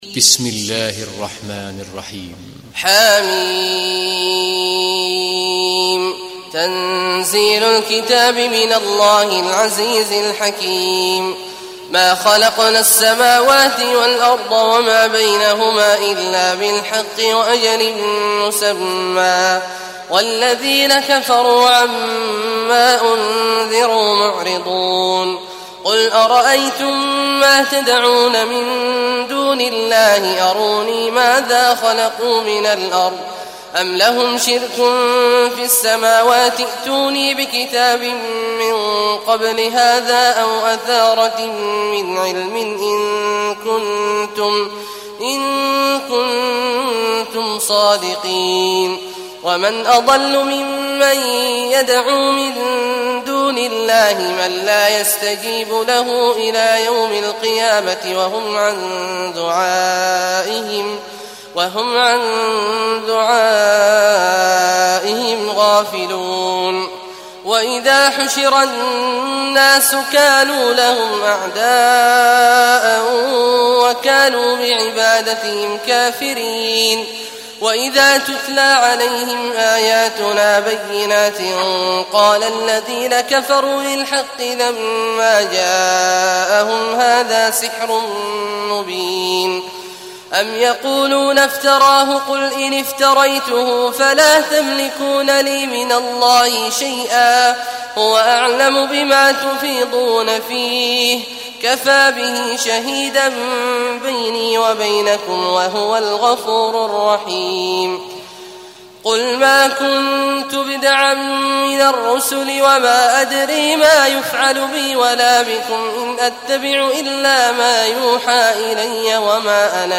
تحميل سورة الأحقاف mp3 بصوت عبد الله عواد الجهني برواية حفص عن عاصم, تحميل استماع القرآن الكريم على الجوال mp3 كاملا بروابط مباشرة وسريعة